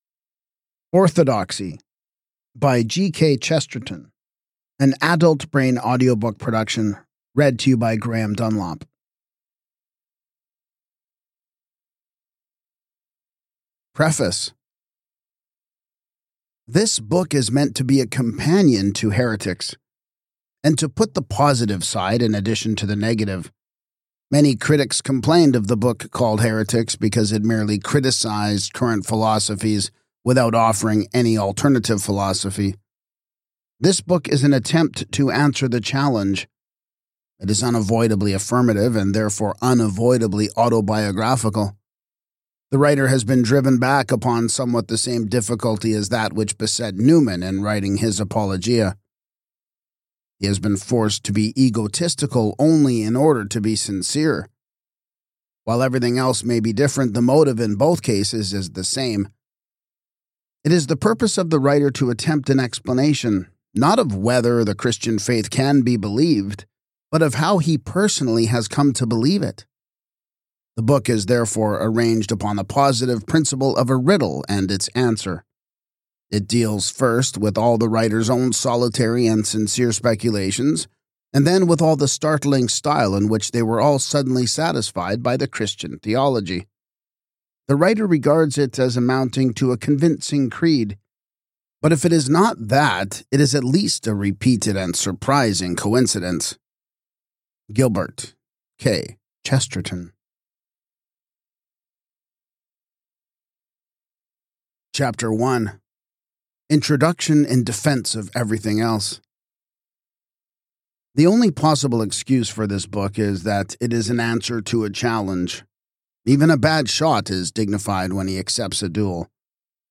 This audiobook preserves the brilliance of Chesterton’s prose while bringing his revolutionary insights vividly to life for today’s audience.